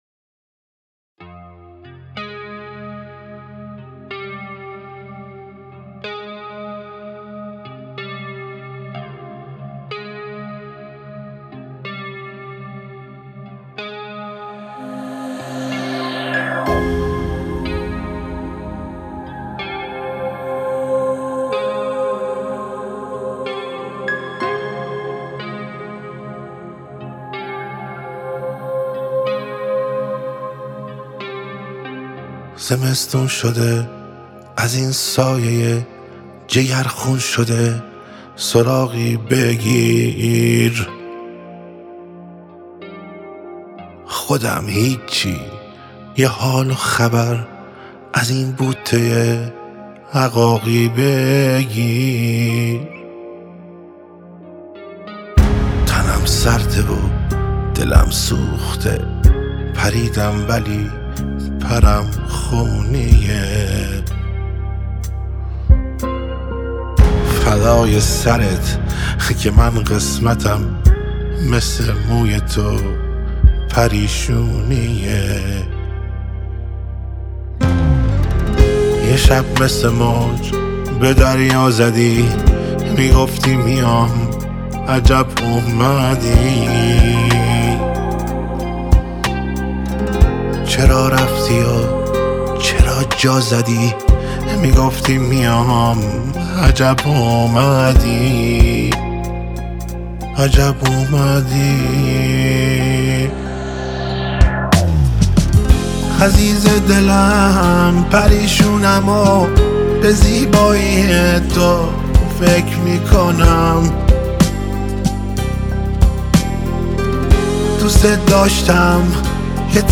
صداش مصنوعیه